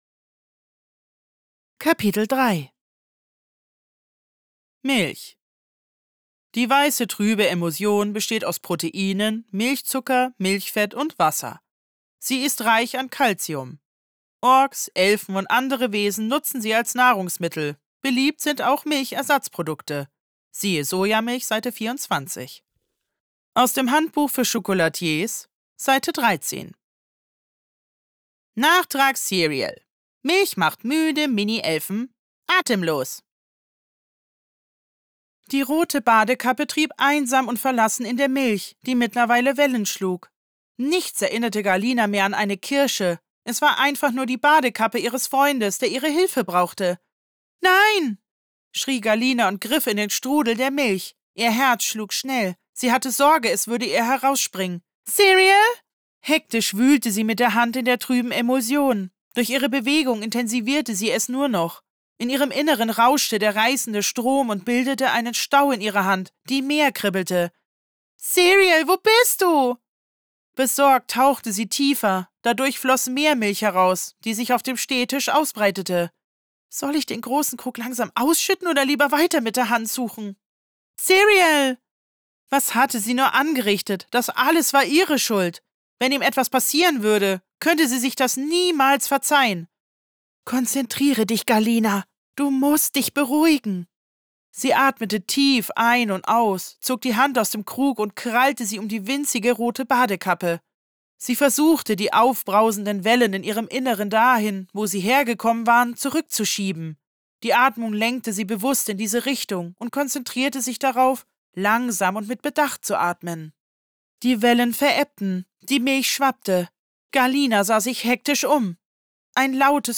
Hörbuch | Fantasy